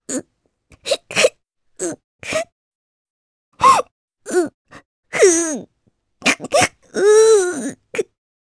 Rephy-Vox_Sad_jp.wav